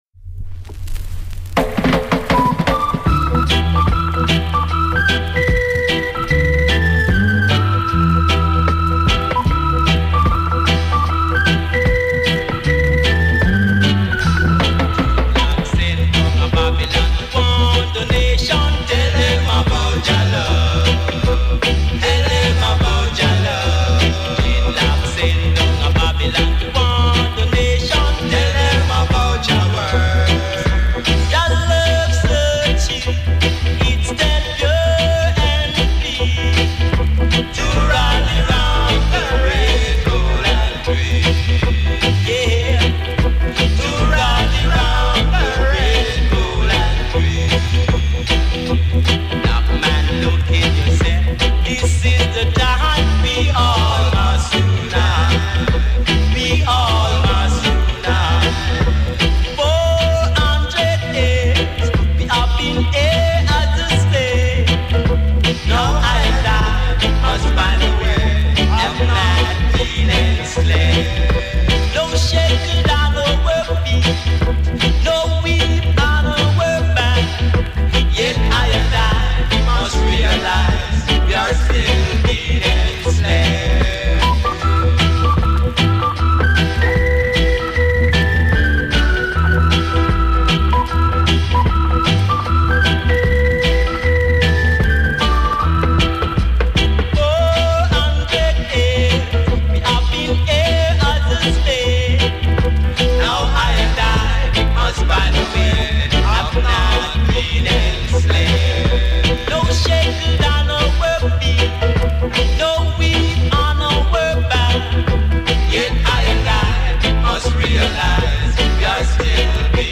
roots & culture